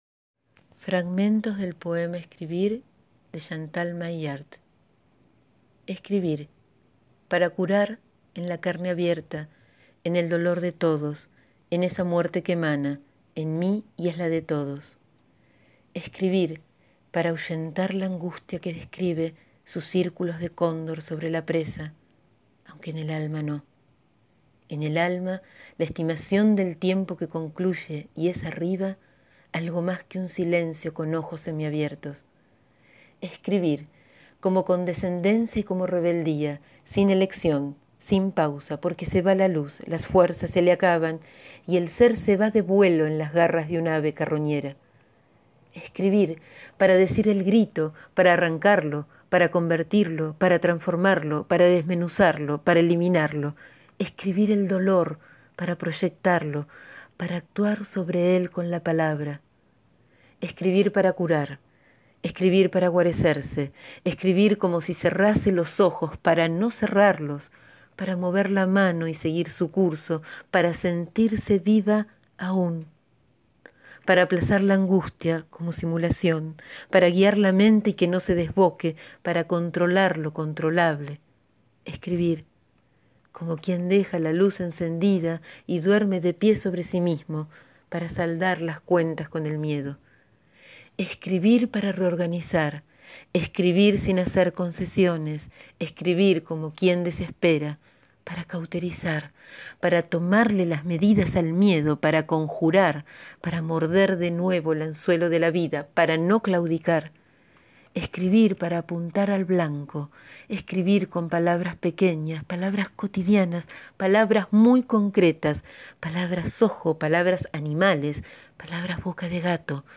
Hoy leo, les leo, fragmentos del poema «Escribir» de Chantal Maillard*